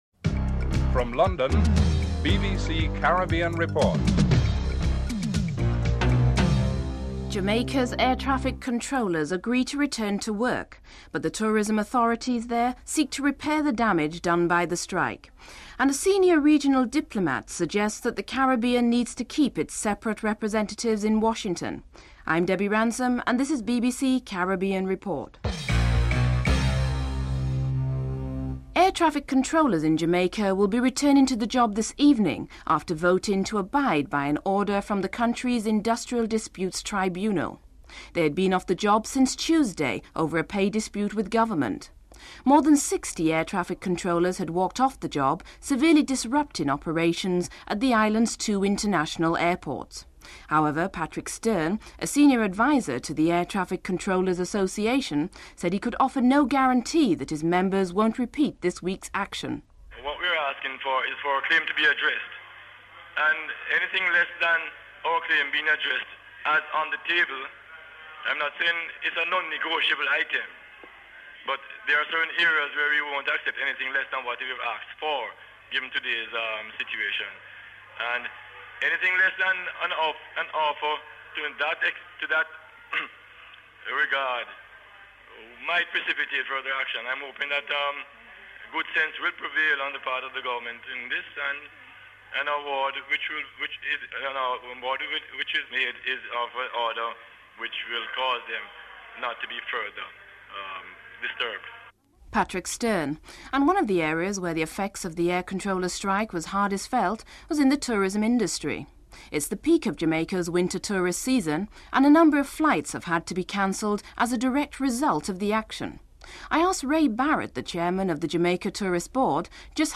Commentator and correspondent Tony Cozier comments that it is clear that the current team has come of age.